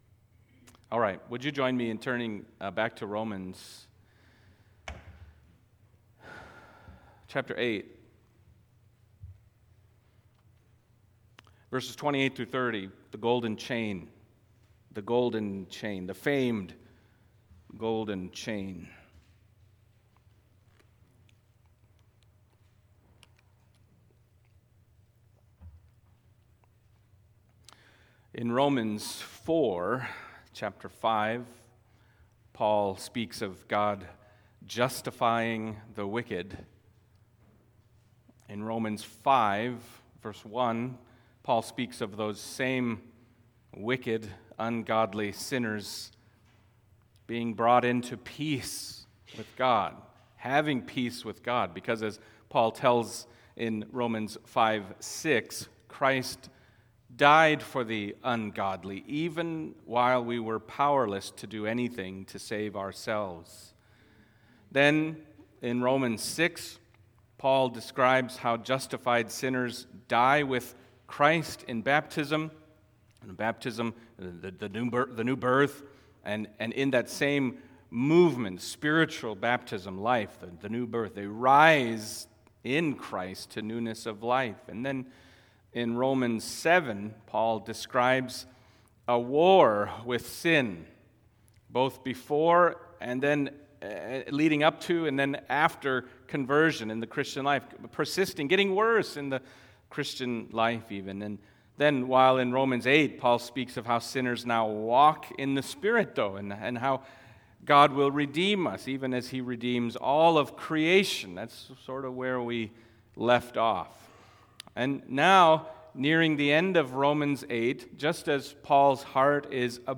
Romans Passage: Romans 8:28-30 Service Type: Sunday Morning Romans 8:28-30 « The Spirit Intercedes For Us God For Us